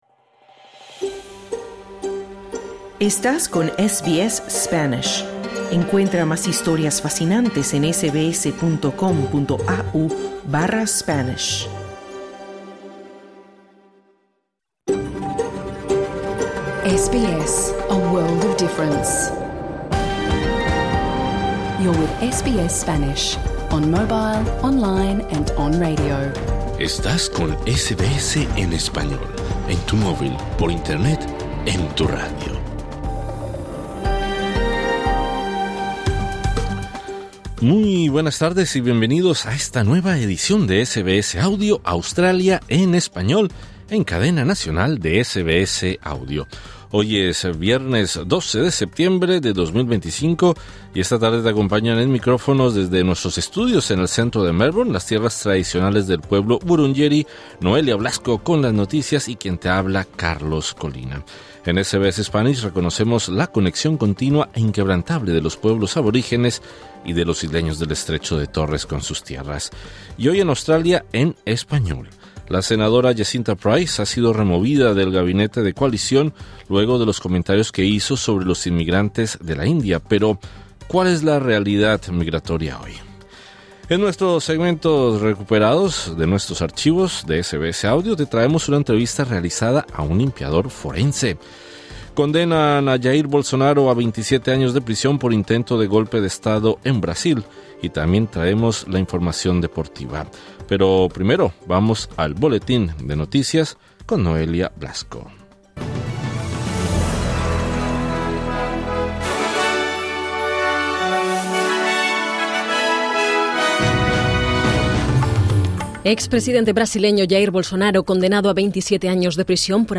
Le preguntamos a un agente migratorio. En nuestros segmentos recuperados de nuestros archivos de SBS Audio te traemos una entrevista realizada a un limpiador forense. Condenan a Jair Bolsonaro a 27 años de prisión por intento de golpe de Estado en Brasil.